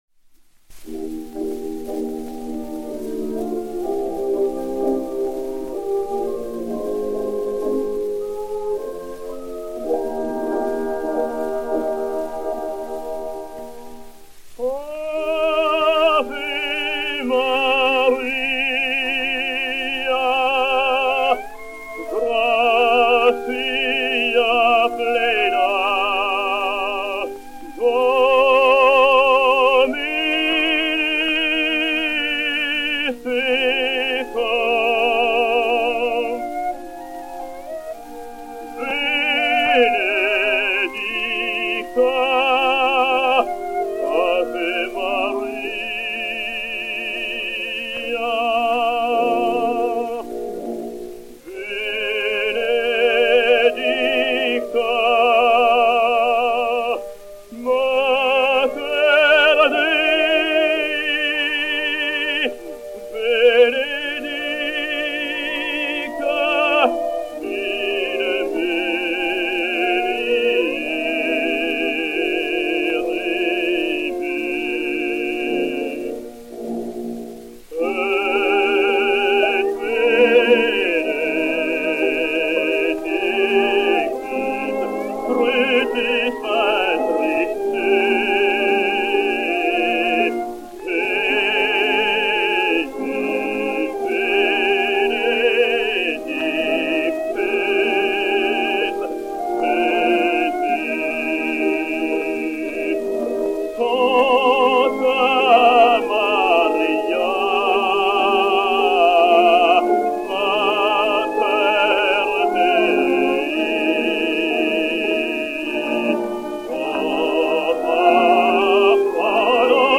Daniel Vigneau et Orchestre